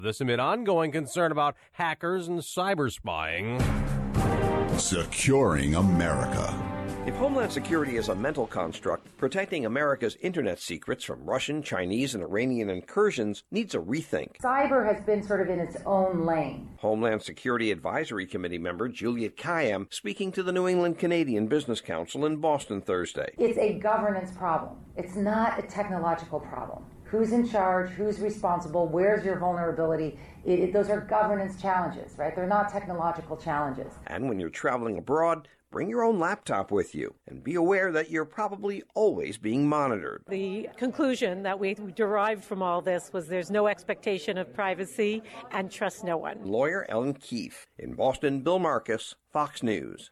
Homeland Security Advisory Committee member and Harvard lecturer Juliette Kayyem speaking Thursday to the New England-Canada Business Council about the practicalities of cyber security and security in general.